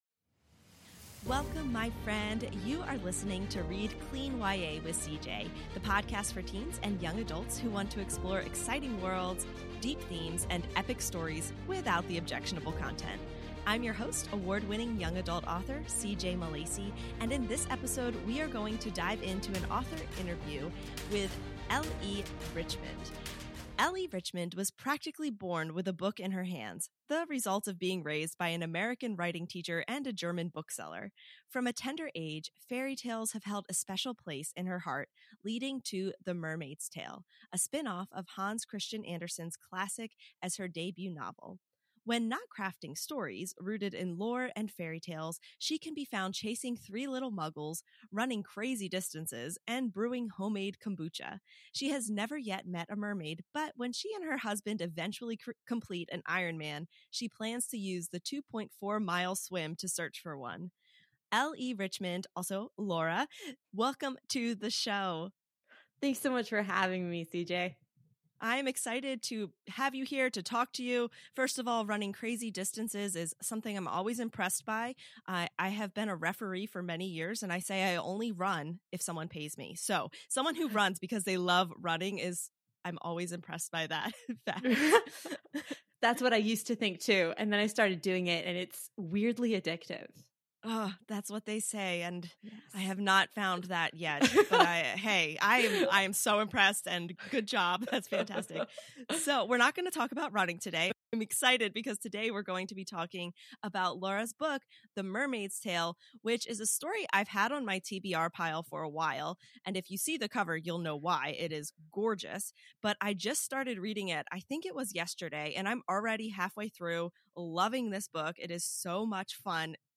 author interview